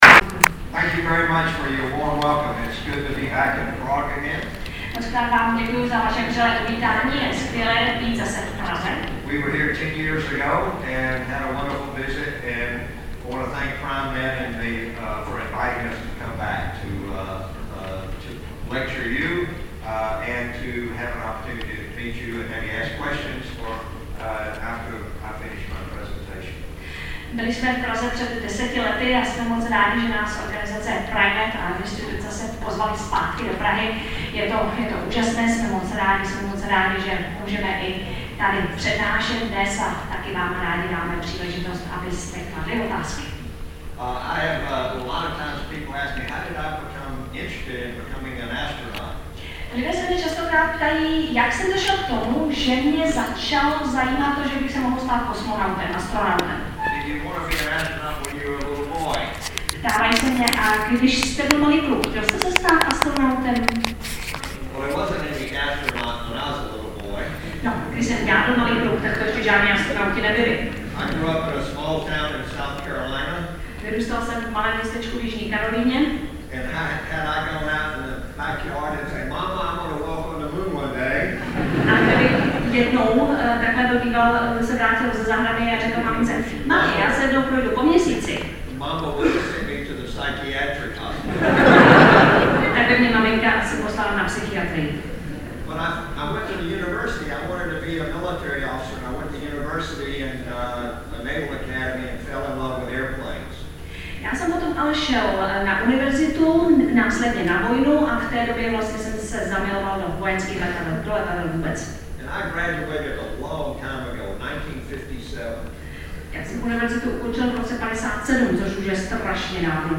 Přednáška amerického astronauta programu Apollo CH. Duka